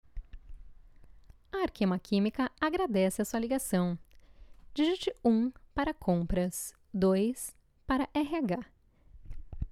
Sweet voice, good for sales, serious.
Sprechprobe: Industrie (Muttersprache):